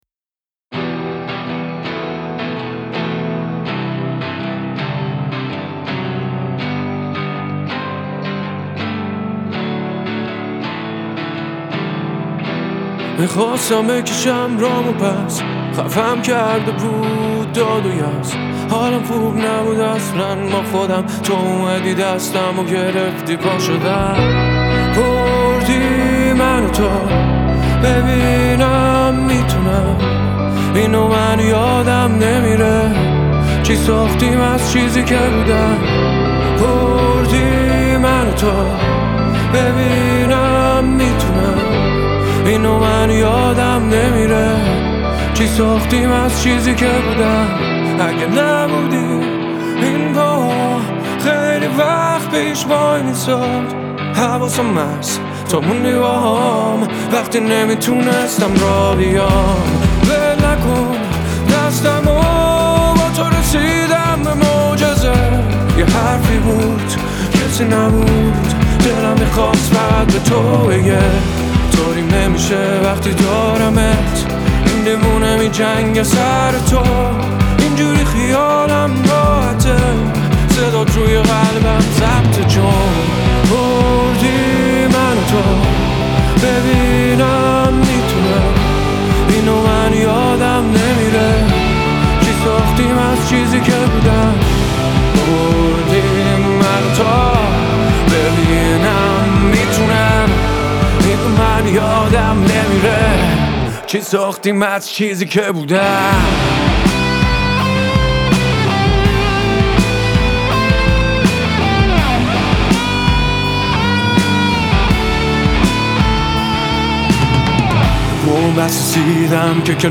موزیک رپ عاشقانه